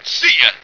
flak_m/sounds/male2/int/M2seeya.ogg at df55aa4cc7d3ba01508fffcb9cda66b0a6399f86